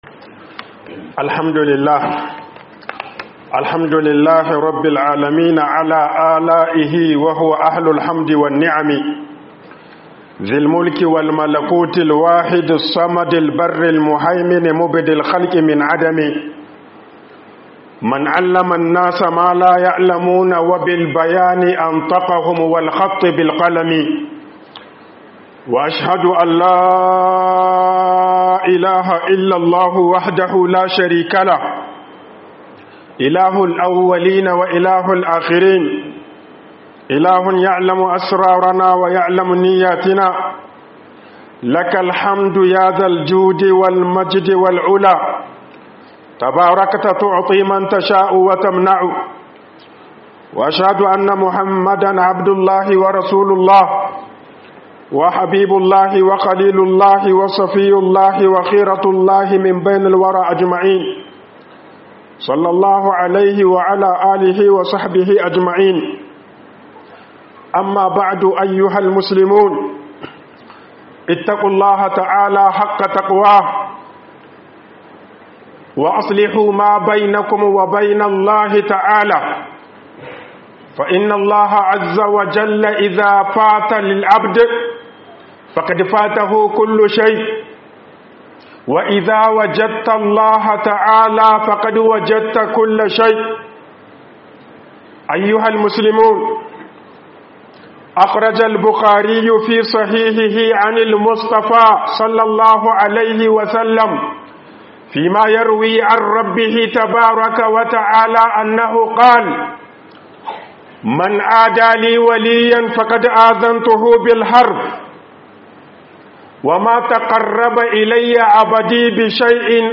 Sirrin Samun Soyayyar Allah Da Waliyyantaka - HUƊUBOBIN JUMA'A